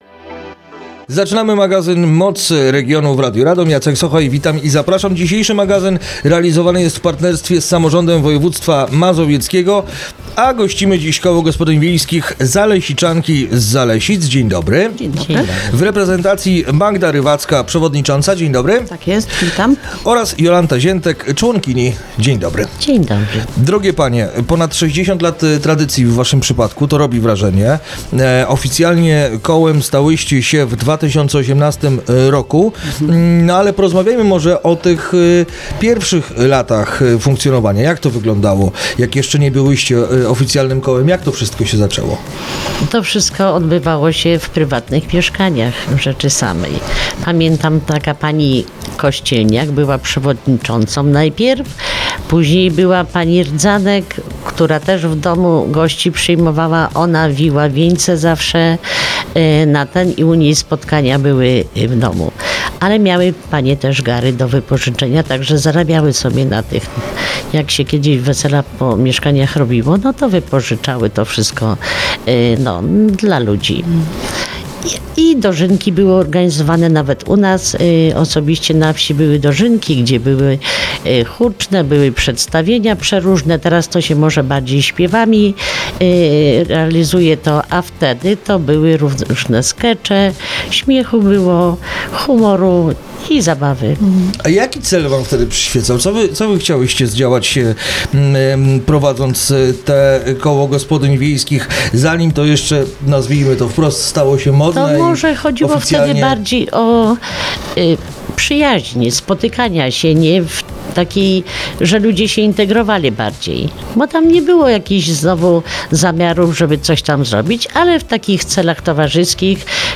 Rozmowa dostępna jest także na facebookowym profilu Radia Radom: